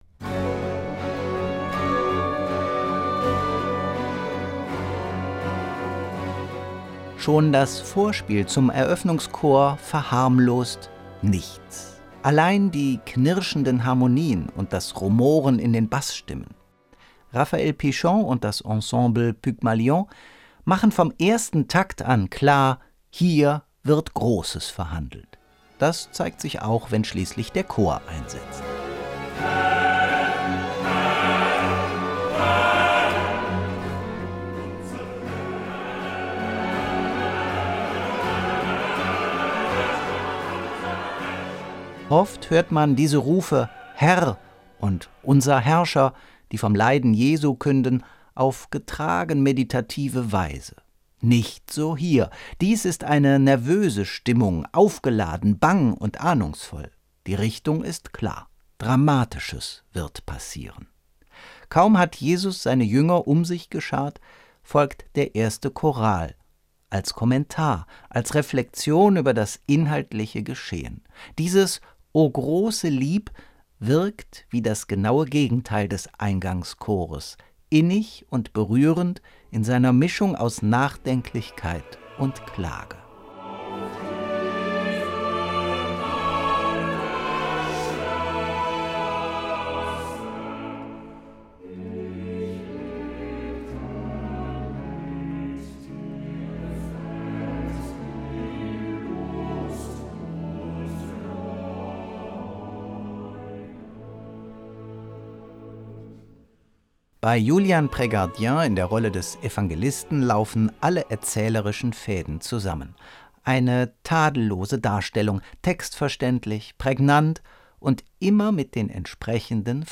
Album-Tipp
Die Musik gewinnt an Dramatik und nimmt den Charakter eines dramatisch erzählenten Oratoriums an.